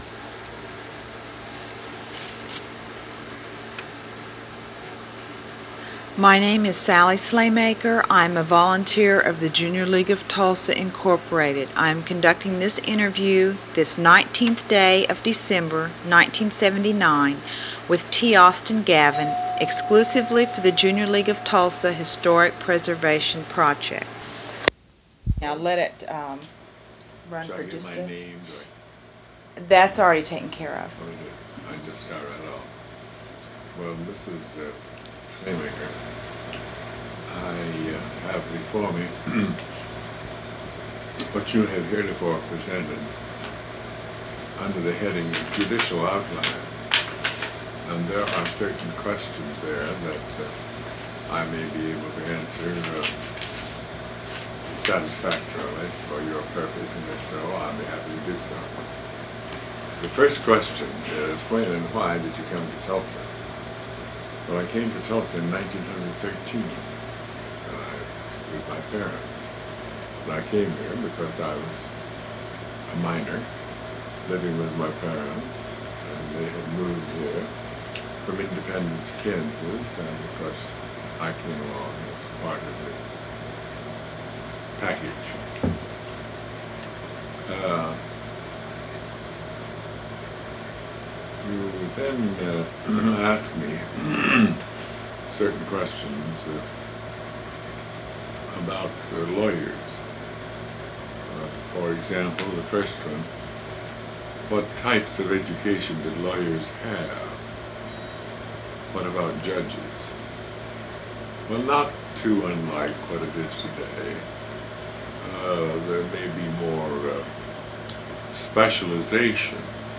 Oral History
The audio quality is poor from about 00:30:00 to 00:50:00.